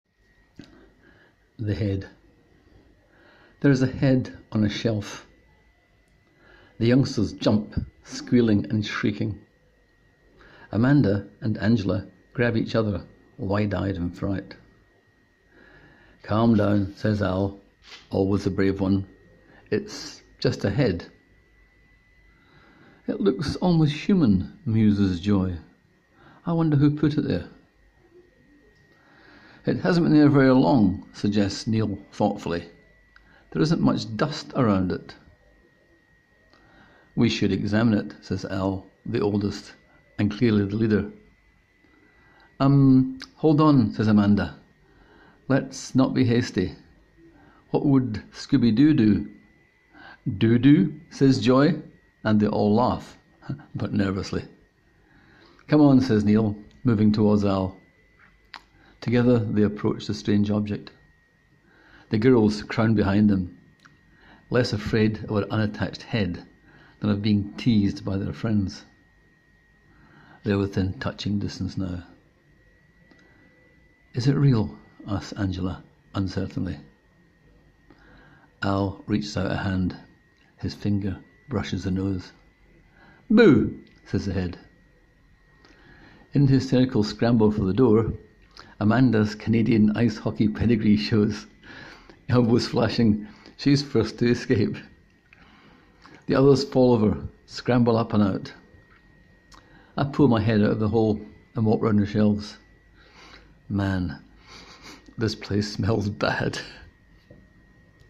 Click here to hear the author read the story:
I noticed you were giggling too by the end of reading that aloud!